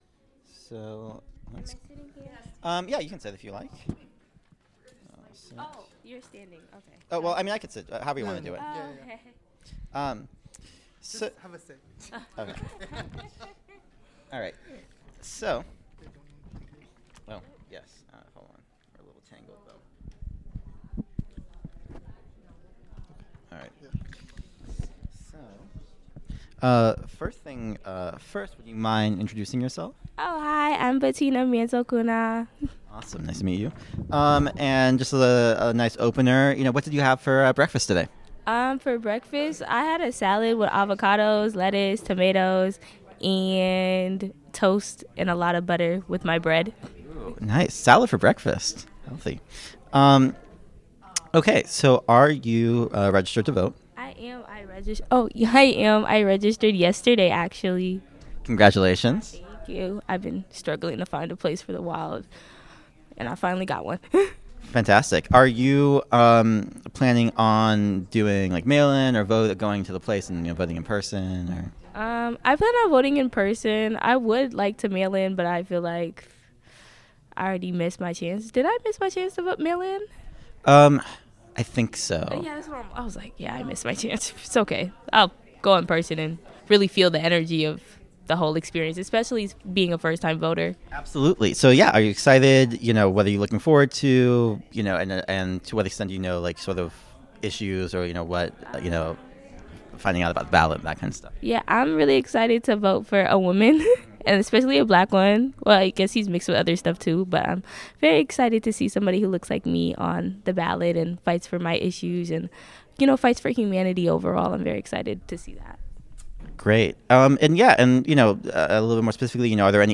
Location Milwaukee Central Library
Interview